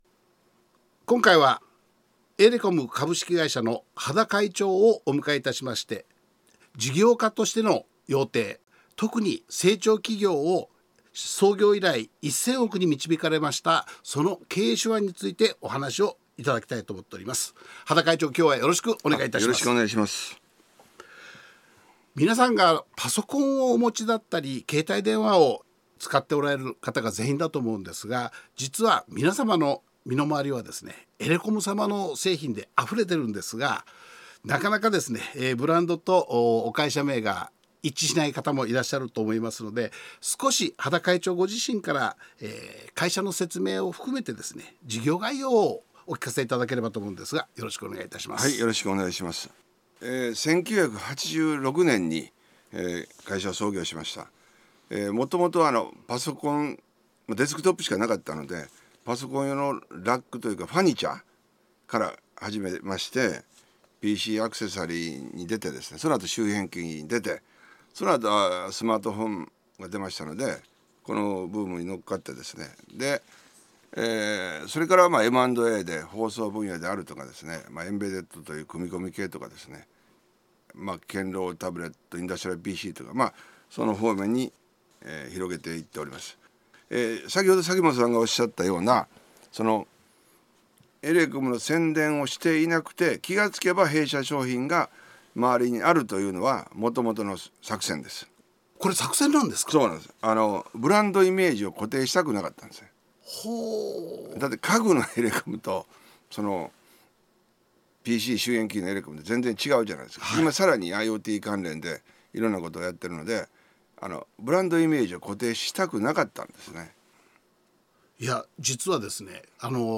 「一代で1000億企業を築く成長と実践の経営」講話のサンプル音声をお聴きいただけます。